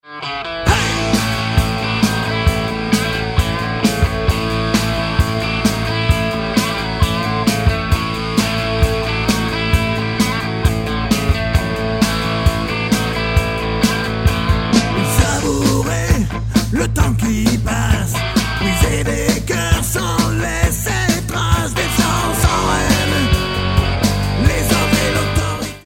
exemple3: gallows2 même chose avec une troisième prise de gratte sur certains passages